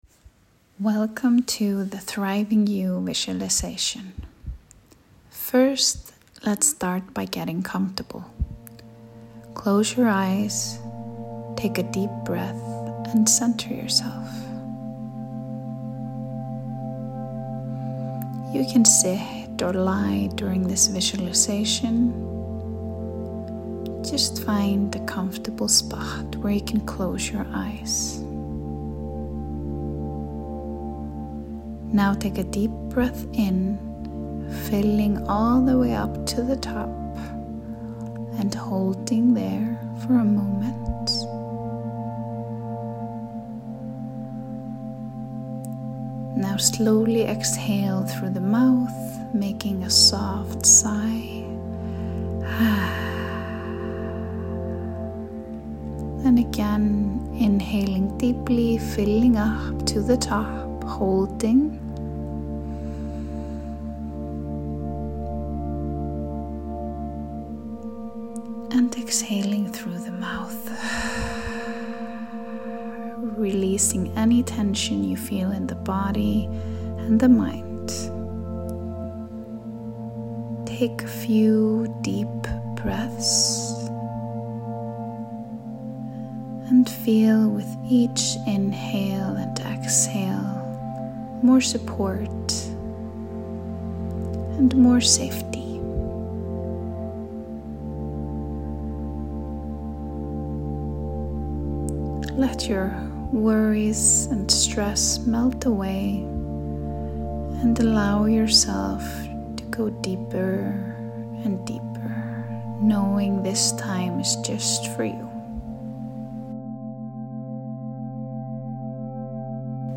A calming, empowering audio ritual to refill your cup and call your energy back home.
This 8-minute guided meditation is a reminder that your thriving matters — not just your giving.